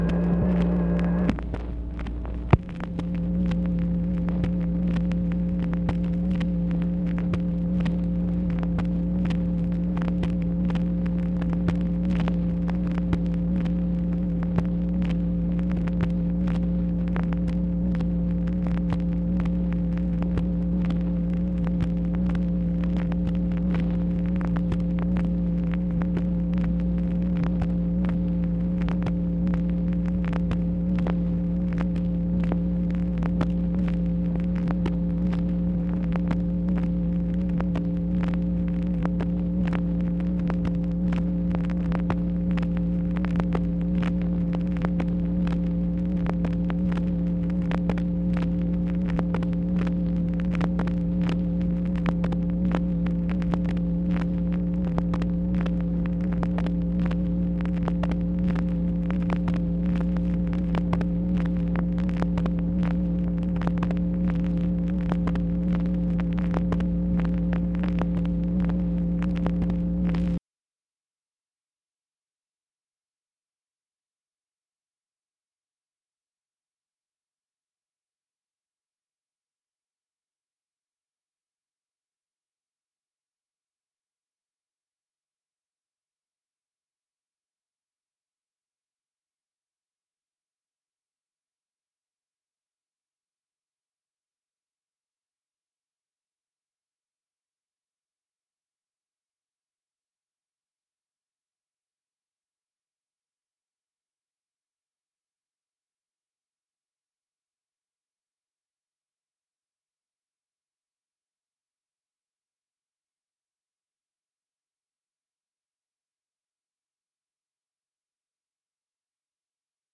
MACHINE NOISE, August 1, 1964
Secret White House Tapes | Lyndon B. Johnson Presidency